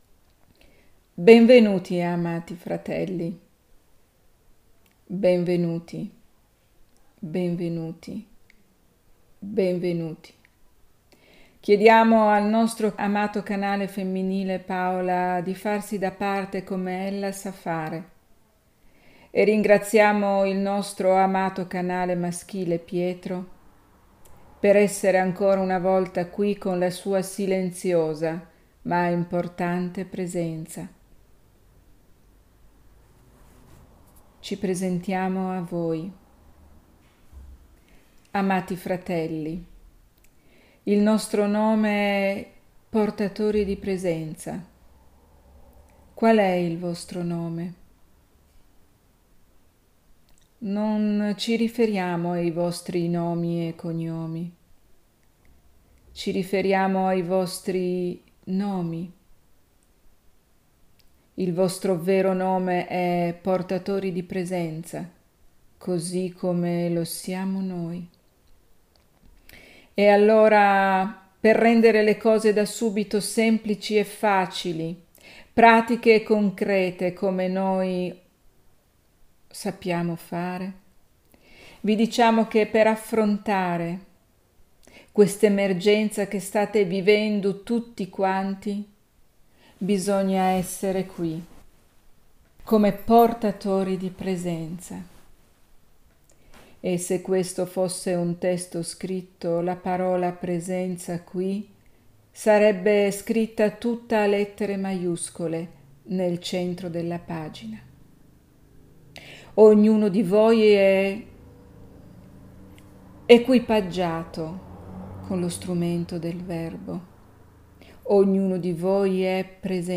[clicca e ascolta la registrazione integrale della sessione di channeling]